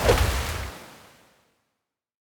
water sword Buff 2.wav